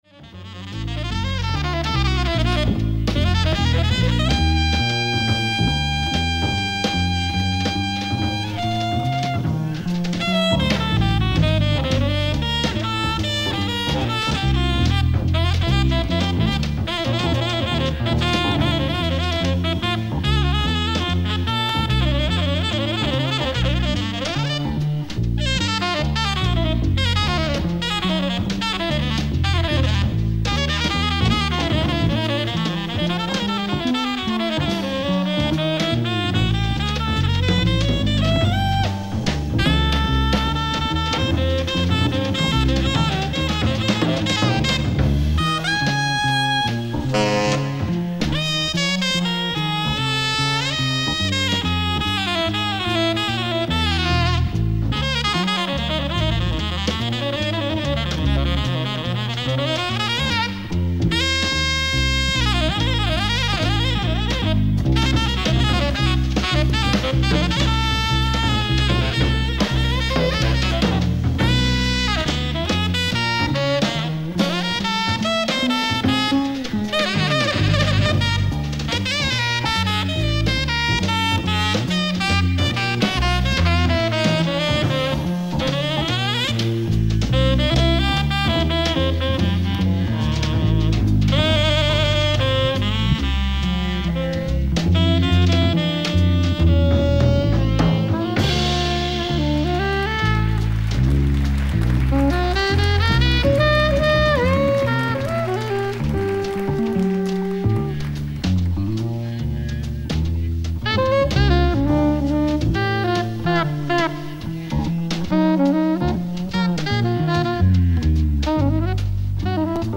ライブ・アット・フェブリック、ハンブルグ、ドイツ
※試聴用に実際より音質を落としています。